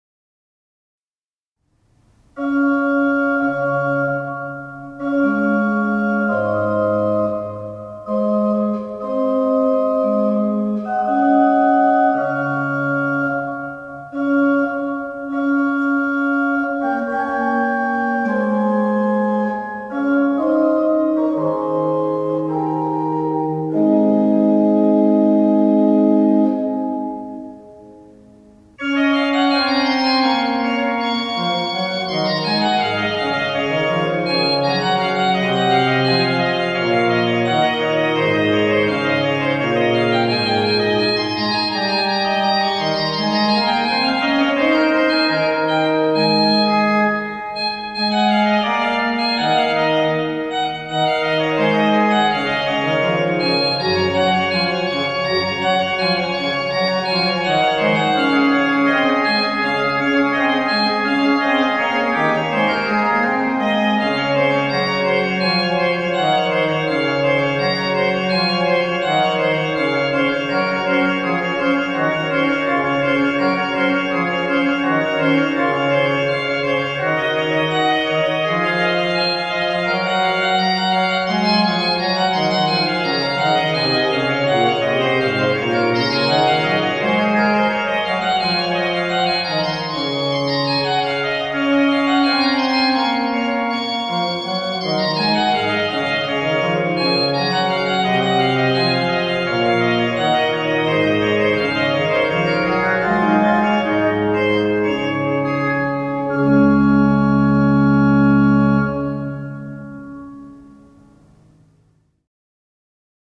Orgelmuziek voor het hele jaar